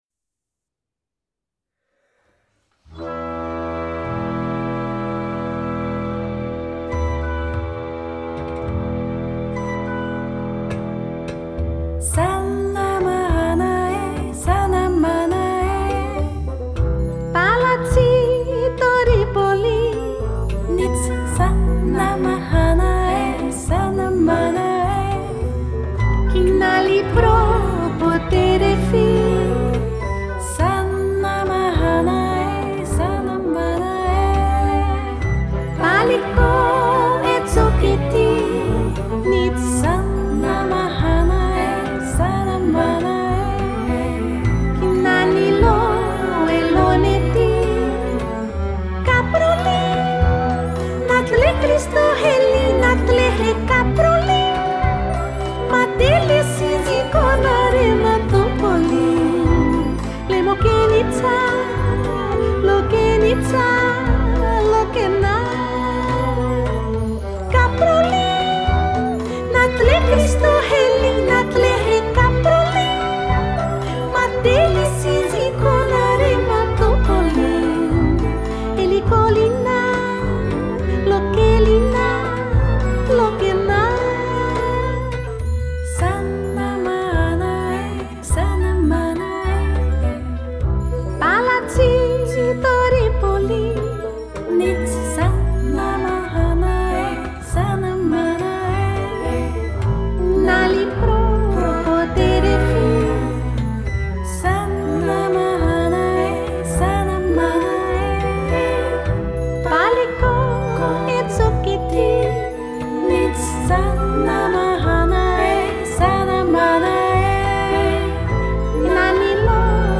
VOCAL VERSION by